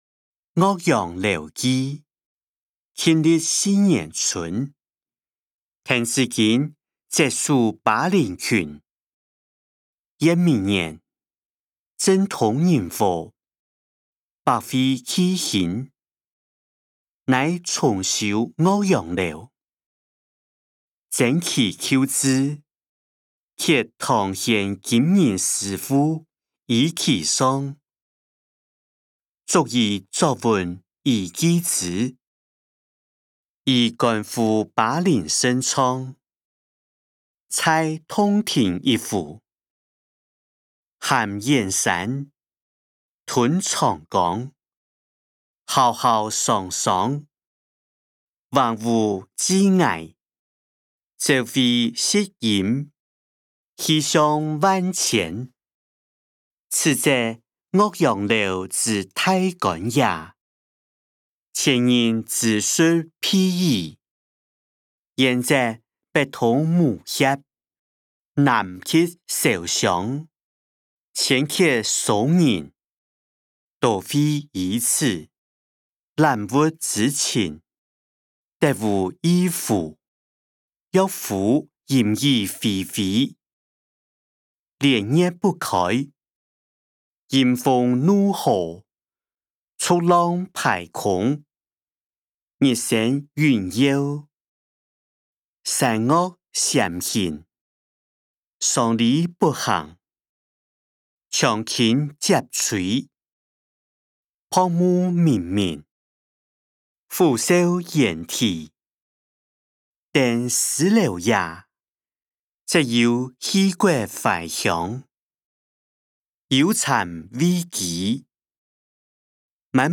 歷代散文-岳陽樓記音檔(四縣腔)